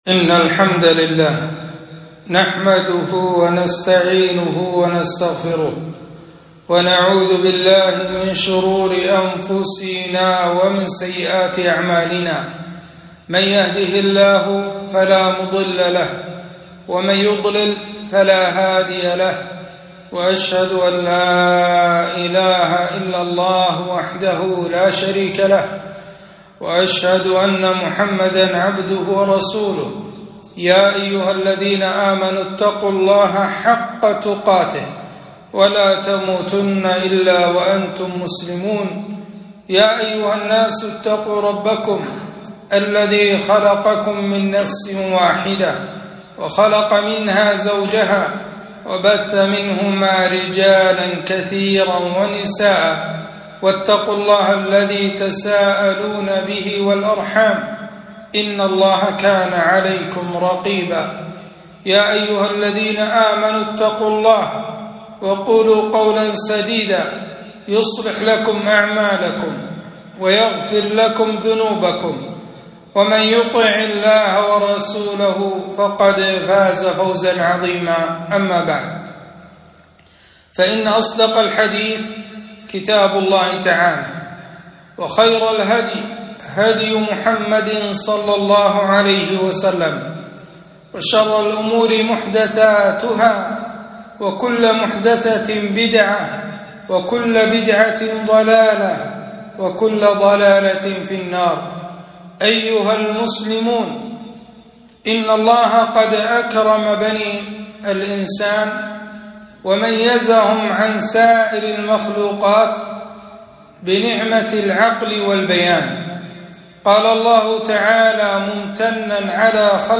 خطبة - ( والذين هم عن اللغو معرضون )